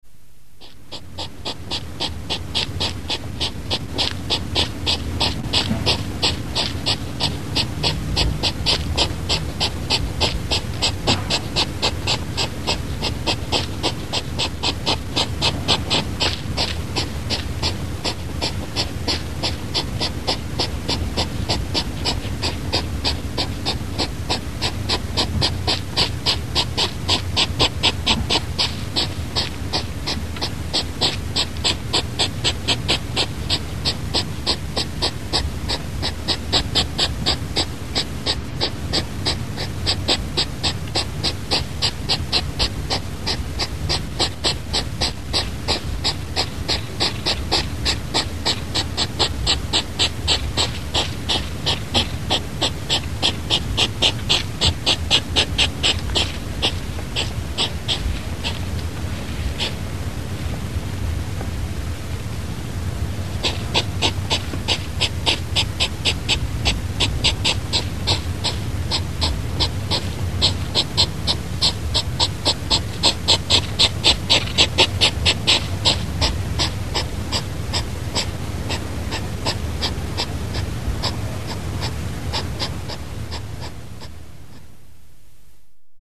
На этой странице собраны натуральные звуки ежей: фырканье, шуршание листьев и другие характерные шумы.
Фырчание ежика звук